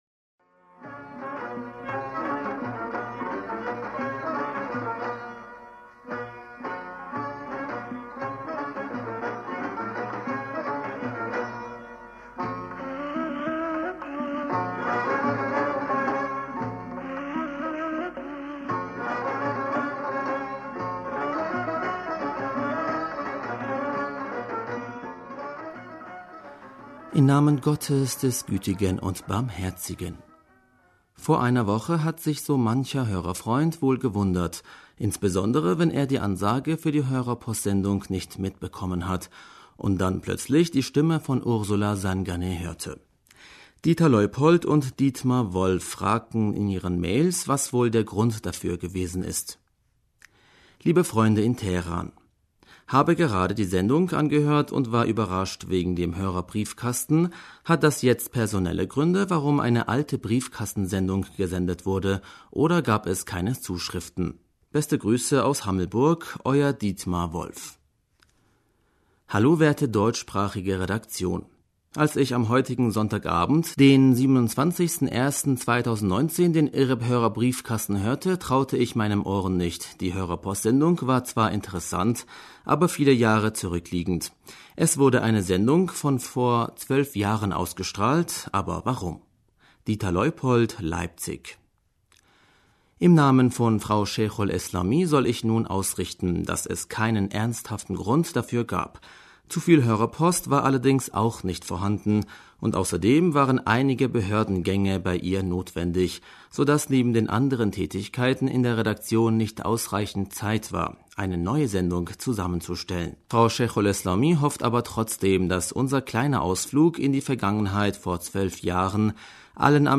Hörerpostsendung am 03. Februar 2019 - Bismillaher rahmaner rahim - Vor einer Woche hat sich so mancher Hörerfreund wohl gewundert, insbesondere w...